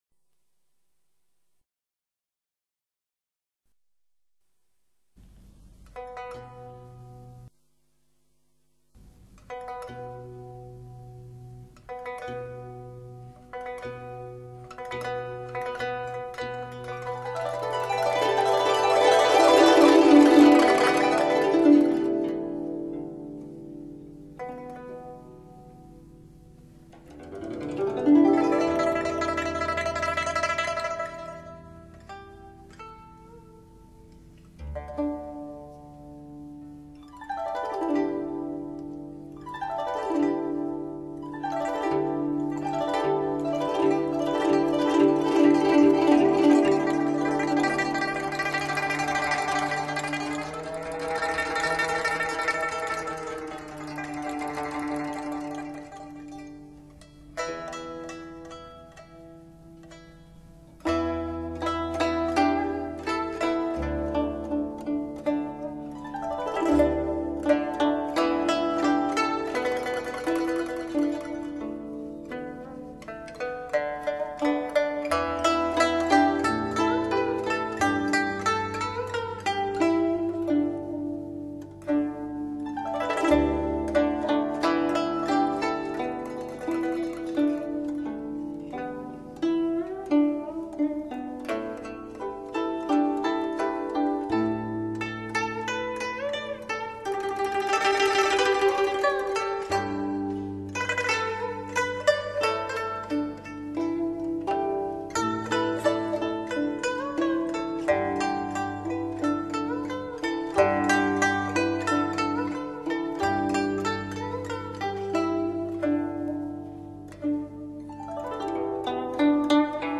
本碟收录了我国优秀的民族乐曲，淡而清雅，